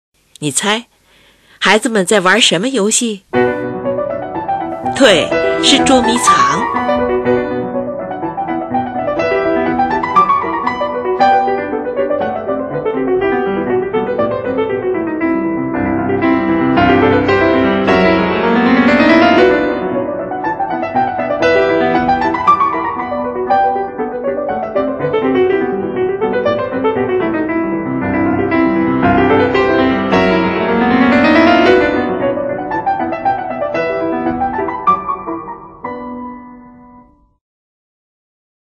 作品手法精炼形象，刻画生动准确，心理描写逼真，欢快动人，饶有情趣，但也流露出一种因为童年逝去而产生的惆怅感。
我们从乐曲那跳跃的伴奏音型里，在上下快速跑动地顿音旋律中，可以回忆起孩提时无忧无虑地追逐玩耍的时光!